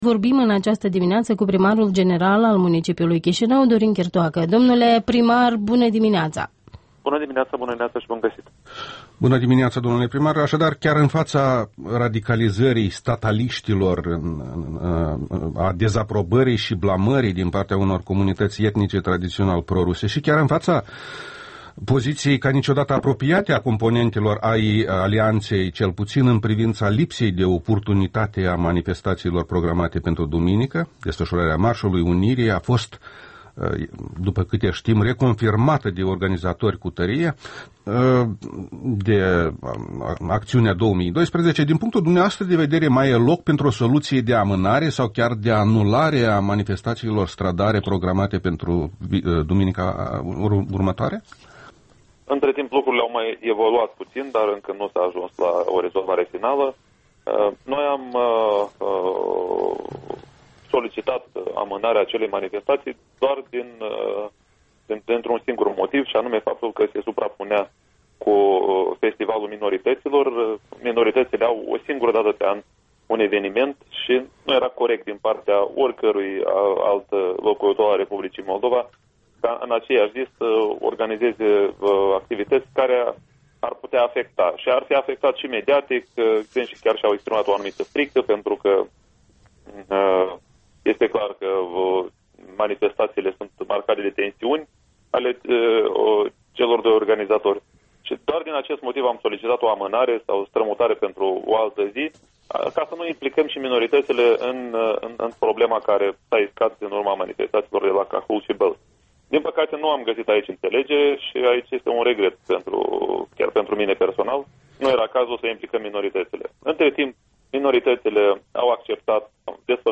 Interviul dimineții: cu primarul Dorin Chirtoacă despre manifestațiile de la 16 septembrie